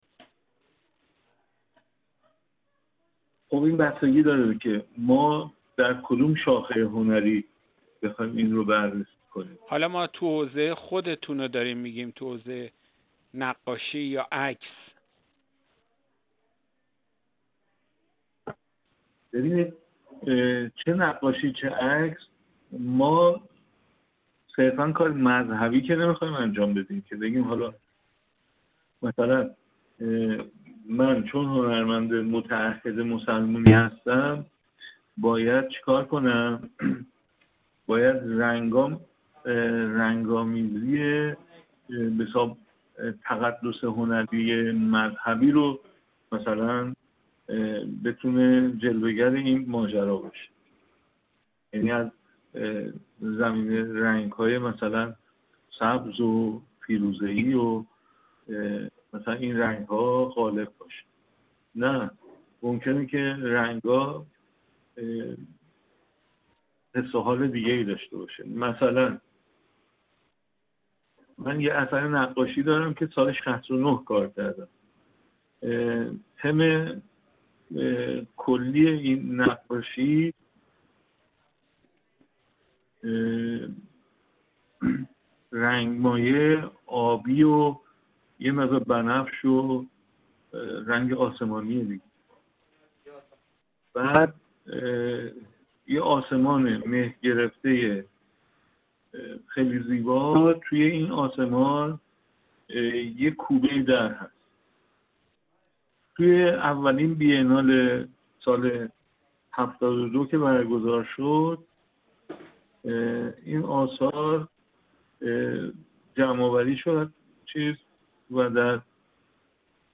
ایکنا – از شما به عنوان هنرمند انقلابی نام برده می‌شود که سال‌هاست در این رابطه آثار متعددی خلق کرد‌ه‌اید. تعریف شما از هنرمند مسلمان ایرانی چیست؟